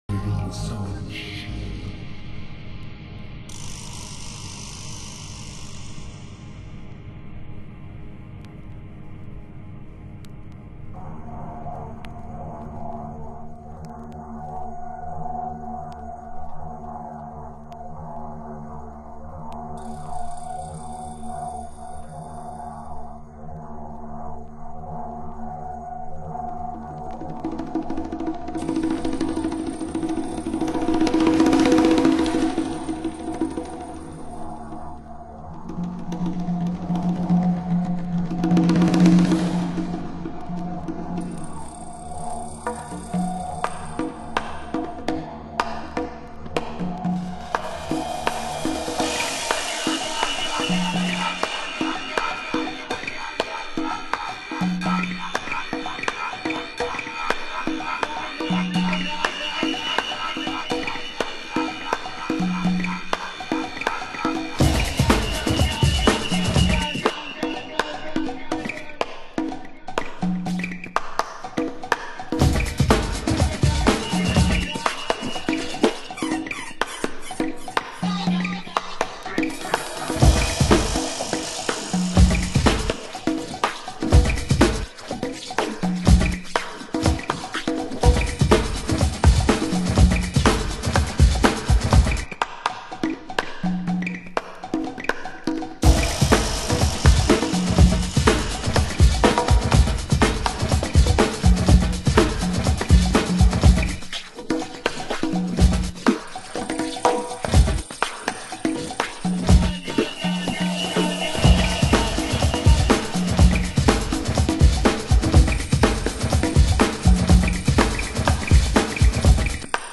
ハウス専門店KENTRECORD（ケントレコード）